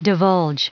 Prononciation du mot divulge en anglais (fichier audio)